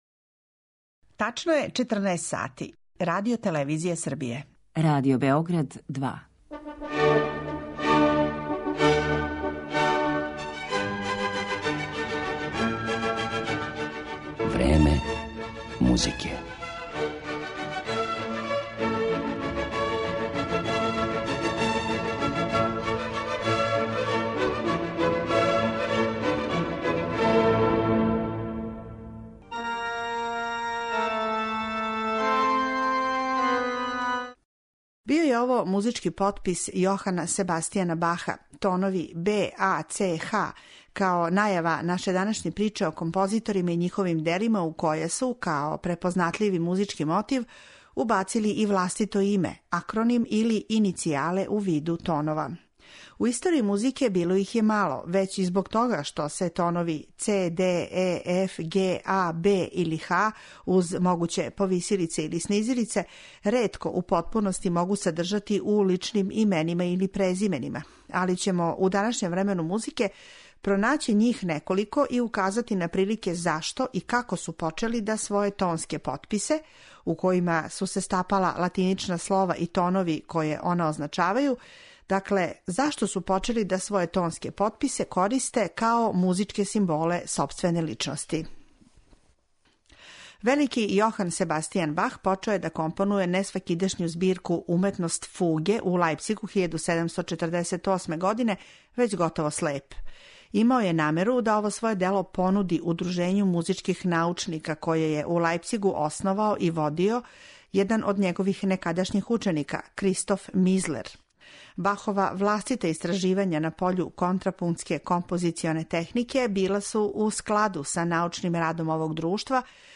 Овога пута слушаћете музику и у њој тонске потписе Јохана Себастијана Баха, Дмитрија Шостаковича, Роберта Шумана и нашег Дејана Деспића.